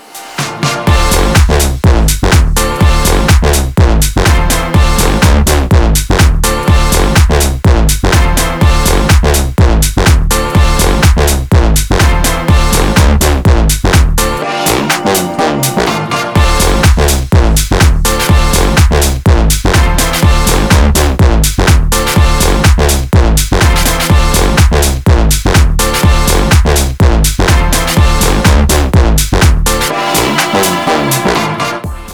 • Качество: 320, Stereo
громкие
EDM
без слов
future house
энергичные
Bass